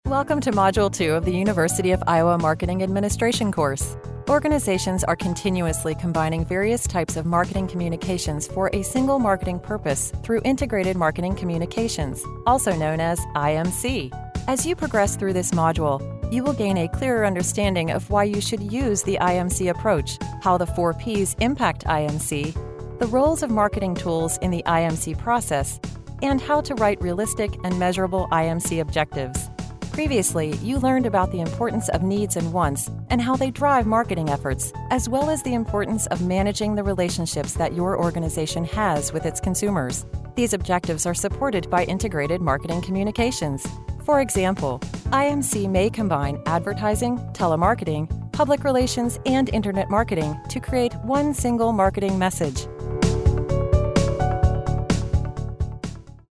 eLearning_Iowa_natural_easy to listen
Neutral, Mid-Atlantic
Middle Aged